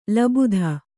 ♪ labudha